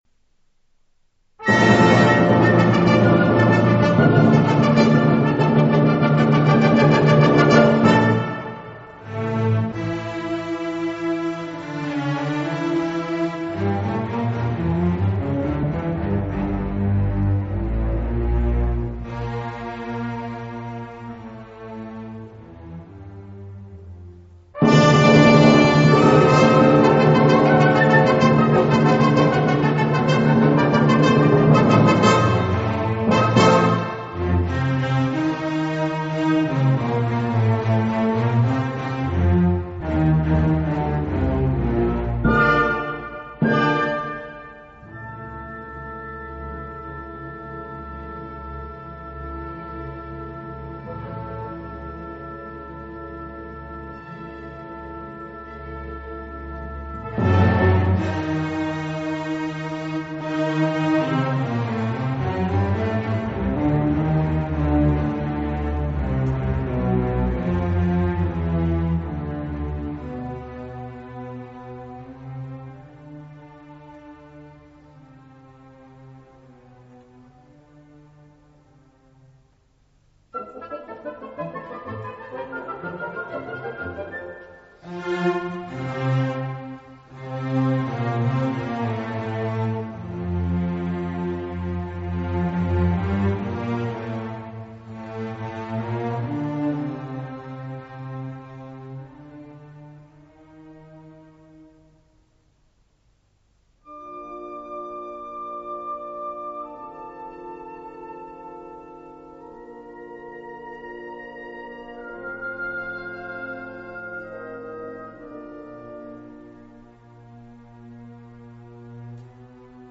CD STEREO
它同时具备了洗练高雅、锐利的情感与慑人的张力。